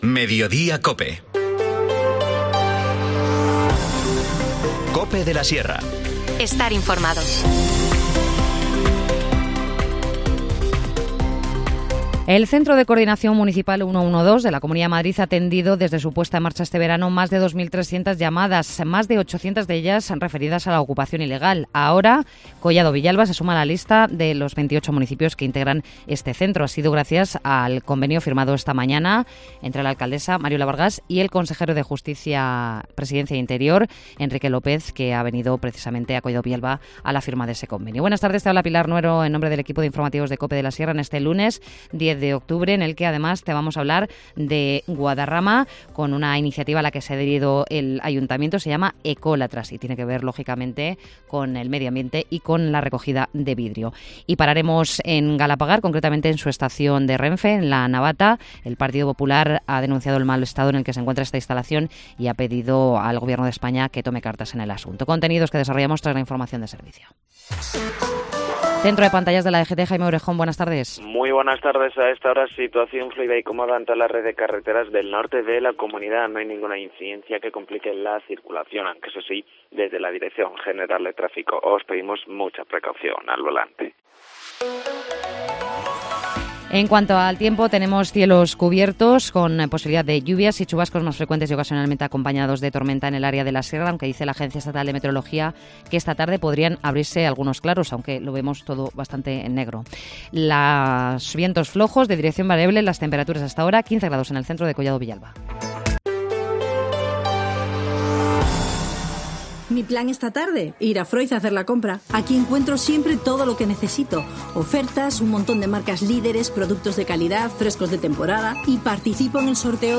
Informativo Mediodía 10 octubre